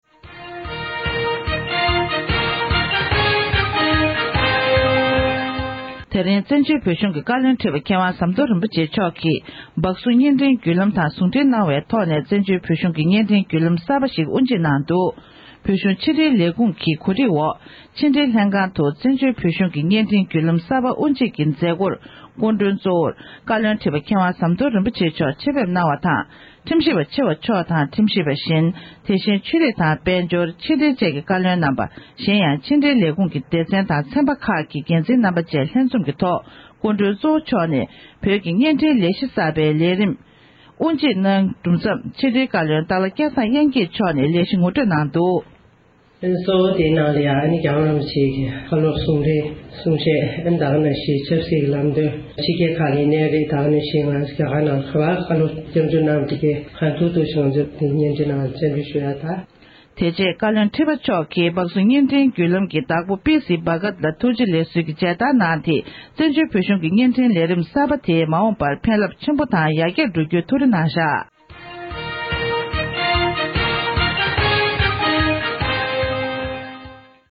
བཀའ་བློན་ཁྲི་པ་མཆོག་ནས་བཙན་བྱོལ་བོད་གཞུང་གི་བརྙན་འཕྲིན་བརྒྱུད་ལམ་གསར་པའི་དབུ་འབྱེད་མཛད་སྒོའི་ཐོག་གསུང་བཤད་གནང་བཞིན་པ།
སྒྲ་ལྡན་གསར་འགྱུར།